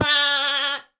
Minecraft Version Minecraft Version latest Latest Release | Latest Snapshot latest / assets / minecraft / sounds / mob / goat / screaming_hurt1.ogg Compare With Compare With Latest Release | Latest Snapshot
screaming_hurt1.ogg